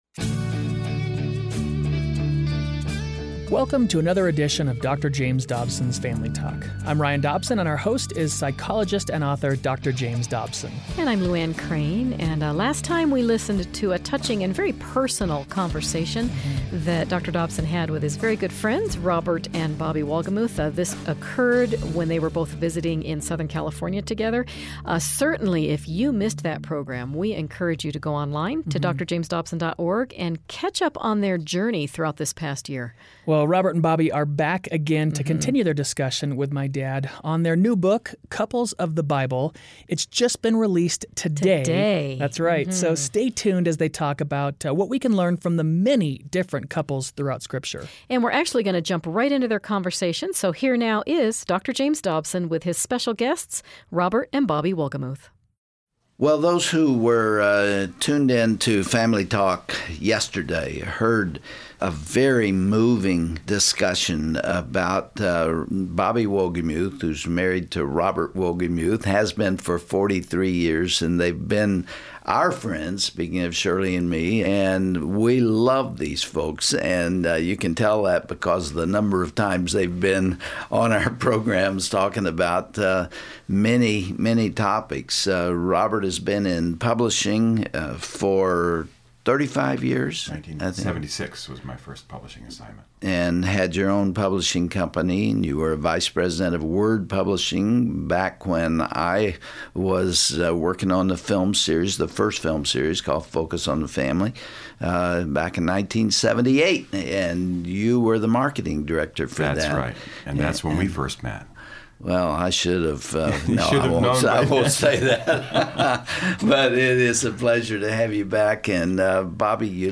This conversation will make you want to dive in with a fresh perspective.